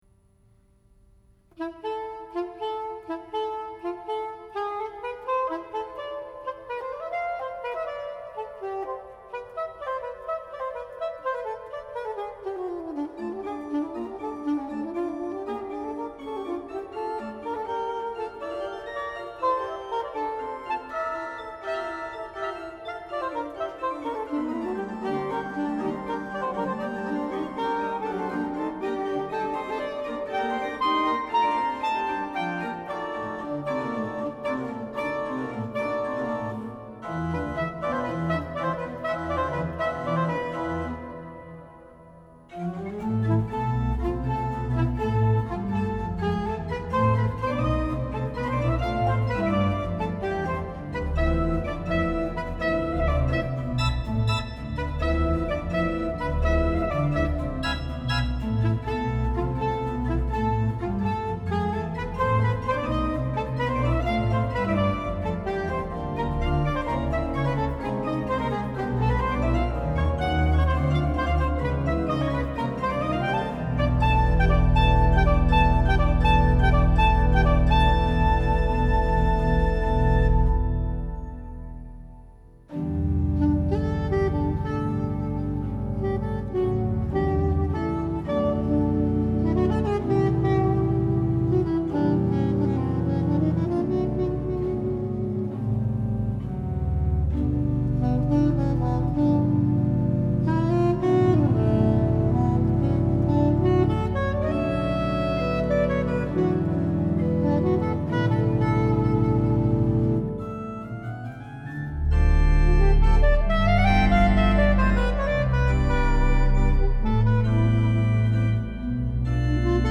en la cathédrale de Saint-Bertrand de Comminges
le tout donnant la part belle à l'improvisation et au jazz.